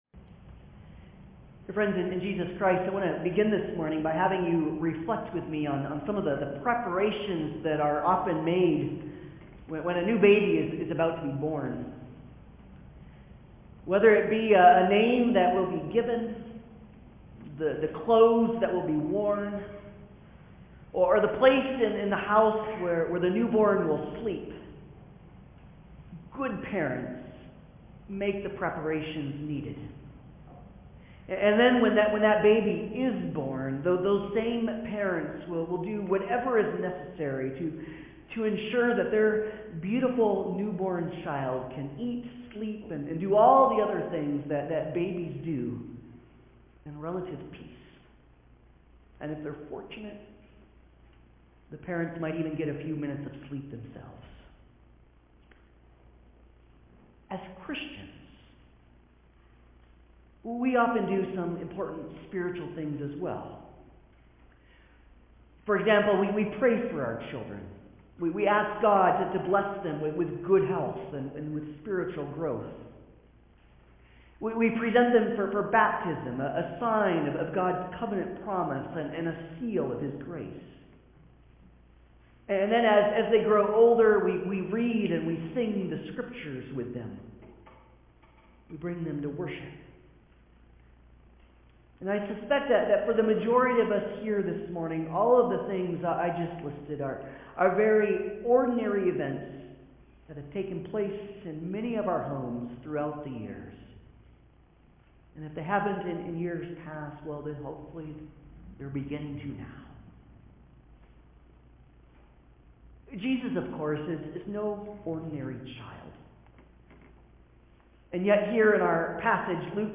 Passage: Luke 2:21-40, II Corinthians 5:21 Service Type: Sunday Service